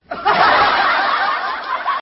bgm-爆笑.mp3